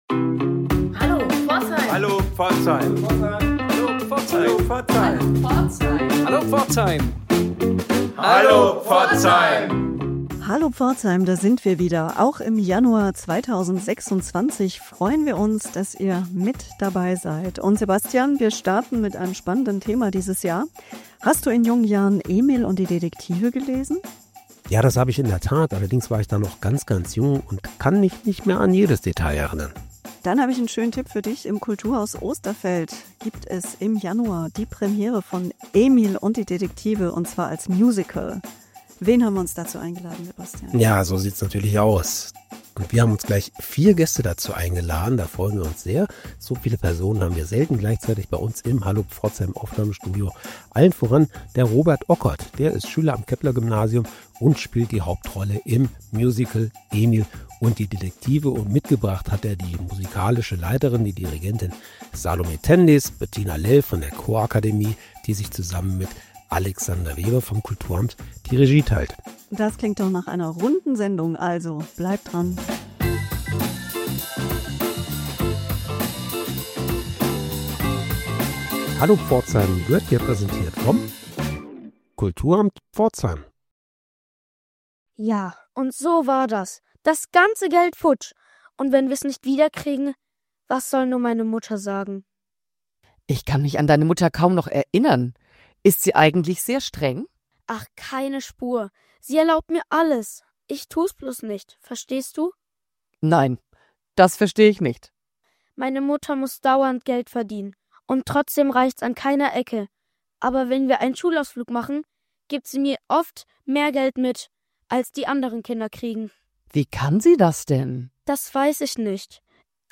Warum Emil auch heute noch begeistert, wie das Berlin der 1920er-Jahre musikalisch auf die Bühne kommt und weshalb dieses Musical nicht nur für Kinder ab 6 Jahren ein Erlebnis ist – darum geht es in diesem Gespräch.